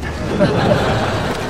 • SHORT AUDIENCE LAUGHTER.wav
SHORT_AUDIENCE_LAUGHTER_KDL.wav